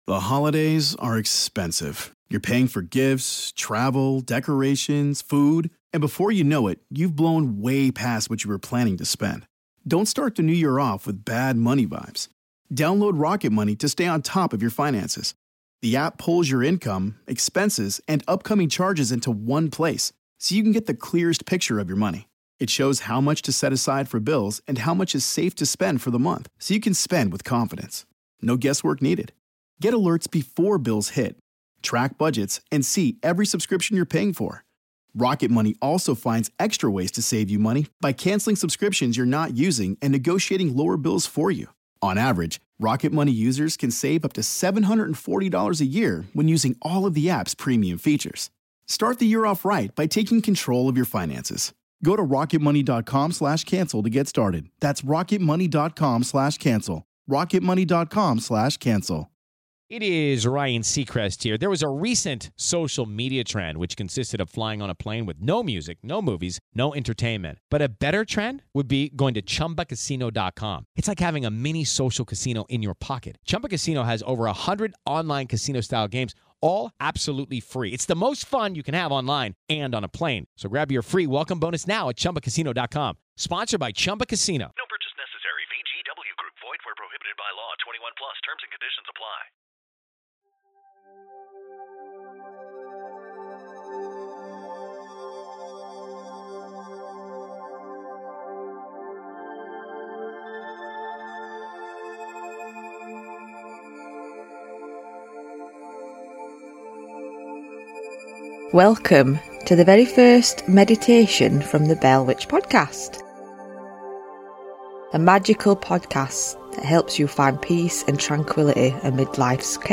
Earth Meditation